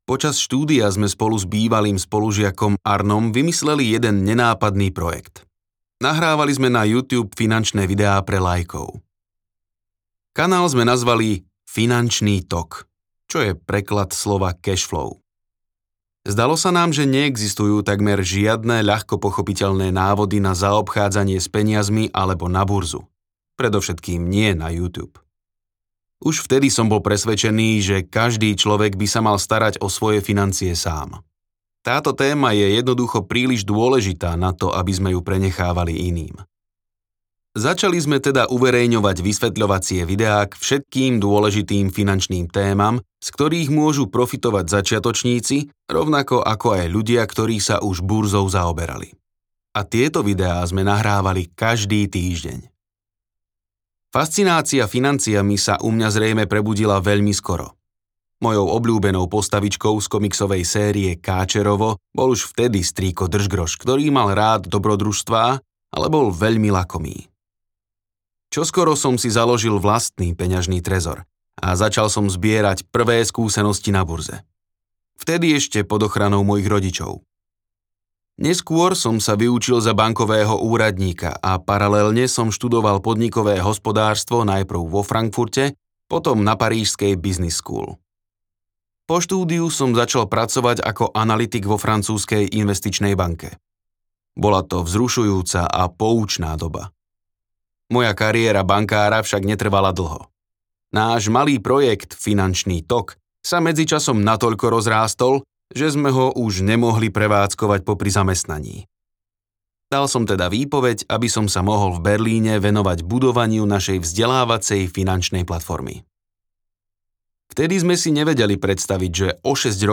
Jediná kniha o financiách, ktorú by ste si mali prečítať audiokniha
Ukázka z knihy